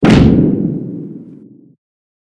mech1_step.ogg